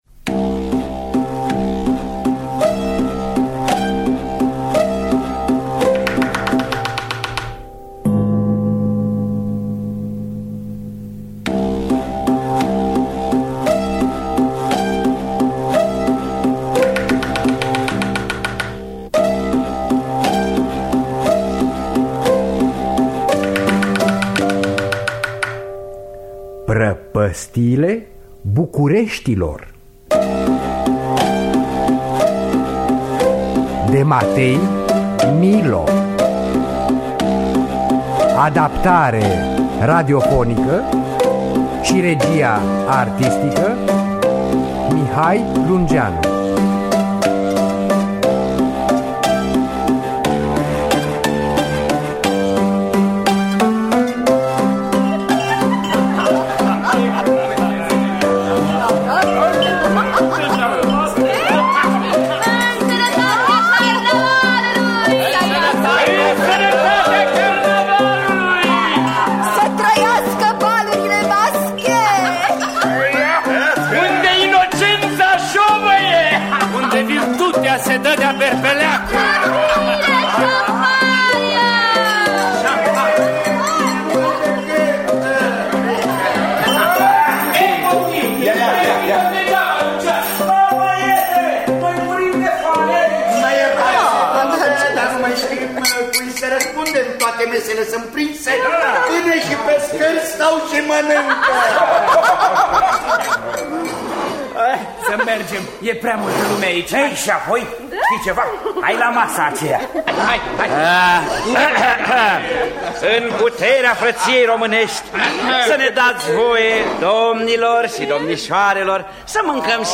Adaptarea radiofonică şi regia artistică